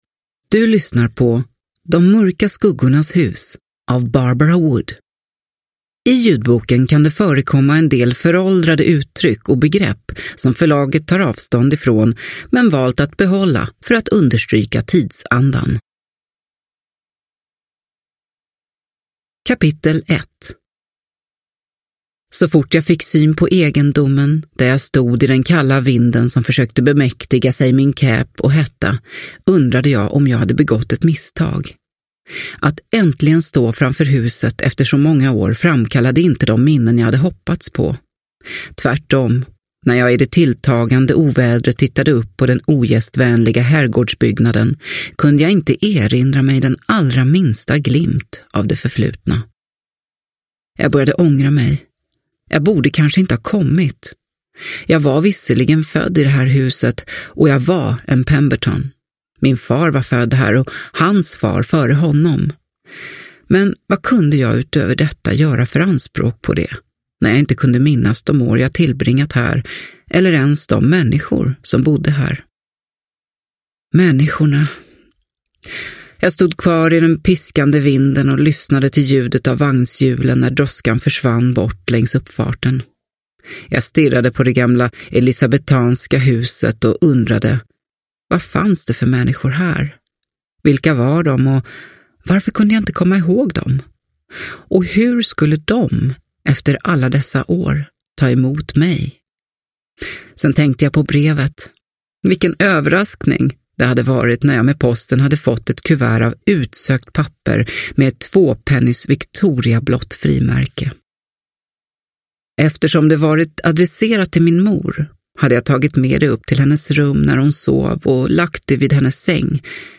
De mörka skuggornas hus – Ljudbok – Laddas ner